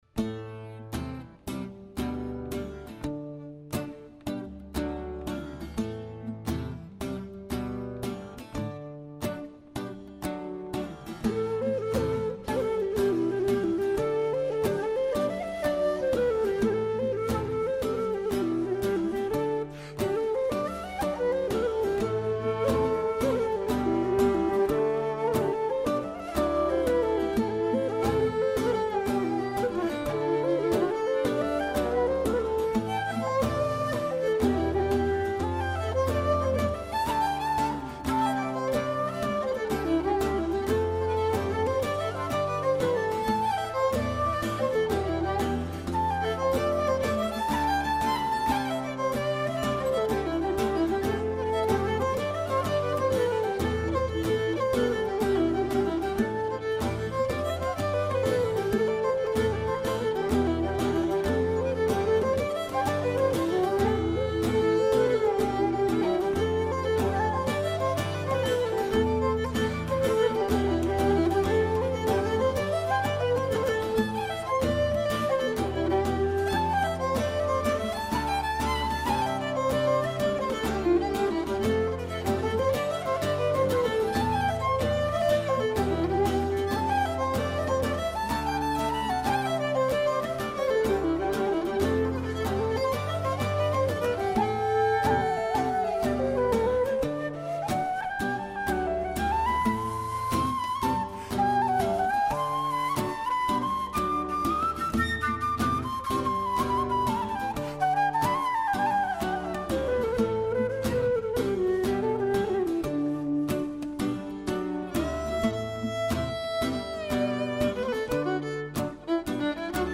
Το όνομα Lúbra, που σημαίνει «λαβύρινθος» στη γαελική γλώσσα, παραπέμπει σε μια μουσική αναζήτηση μέσα από τα μελωδικά μονοπάτια της Ιρλανδίας, της Σκωτίας, της Αγγλίας και άλλων περιοχών όπου άνθησαν οι κέλτικες παραδόσεις — πάντοτε μέσα από το ιδιαίτερο αισθητικό πρίσμα των κρητικών μουσικών καταβολών των μελών του σχήματος.
κρητική λύρα
κρητικά πνευστά
κρητικό λαγούτο 958FM Καλημερα Εκπομπές ΕΡΤ3